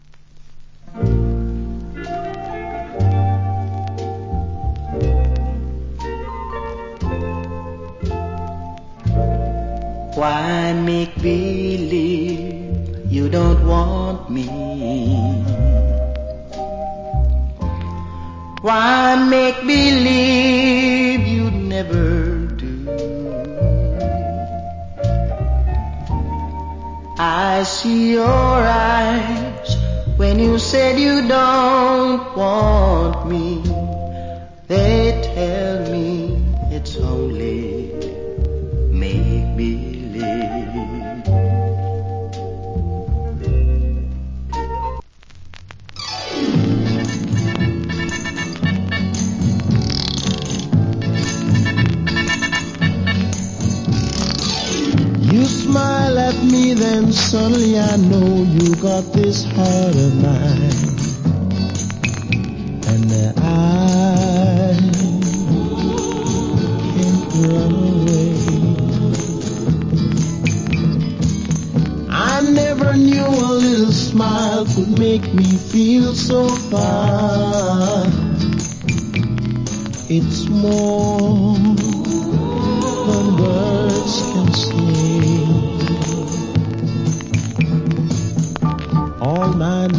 Nice Ballad Vocal.